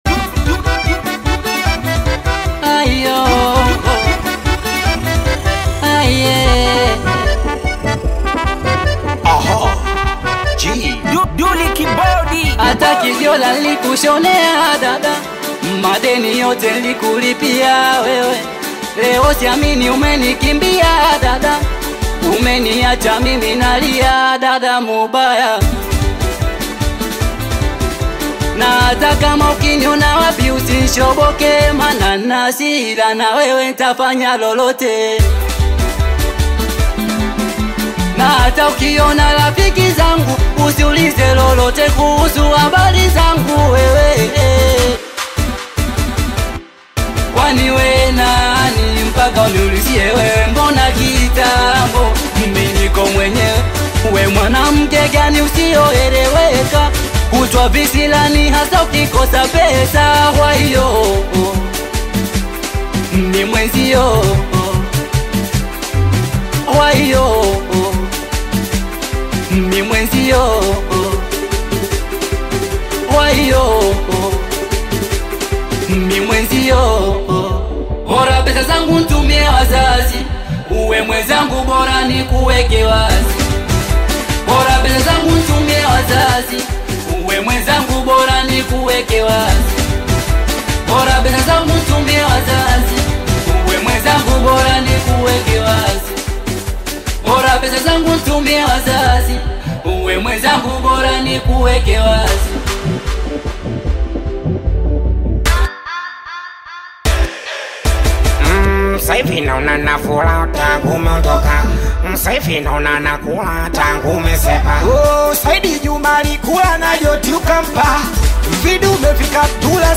is an upbeat Singeli single
Genre: Singeli